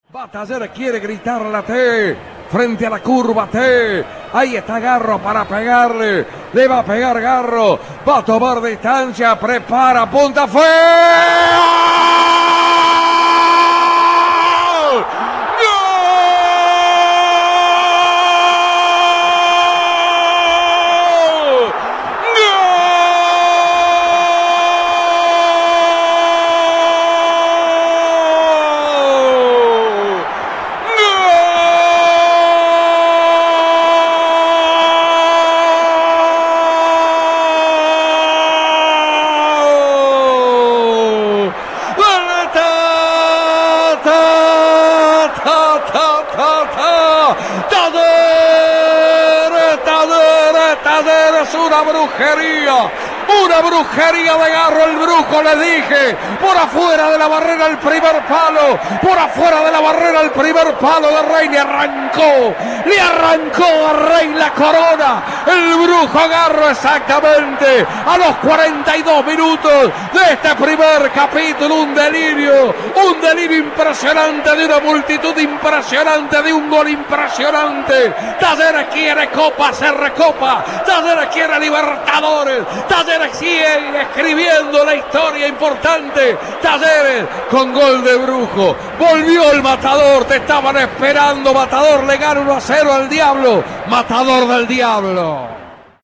Gritos de América: reviví el relato de los tantos del "Matador" ante "El Rojo"